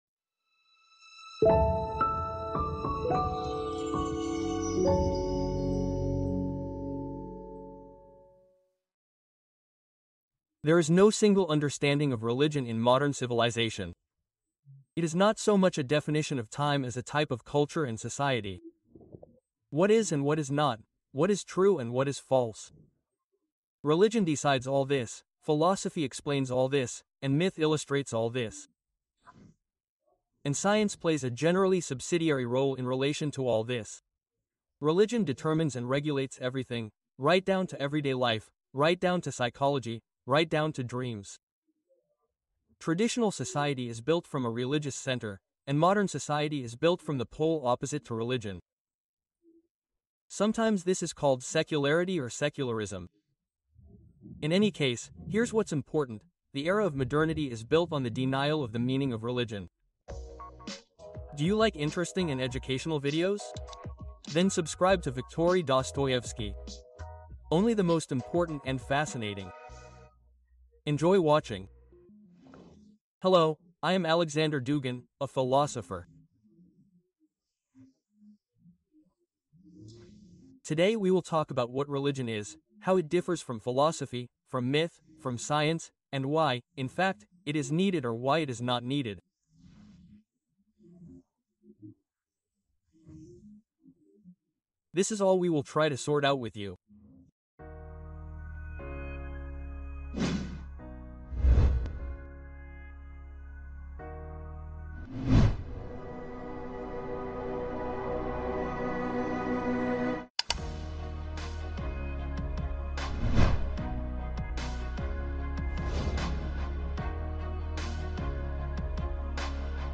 Philosopher Alexander Dugin will reveal the concept of religion in the context of the historical development of society in a new lecture on the Dostoevsky Lecture Channel.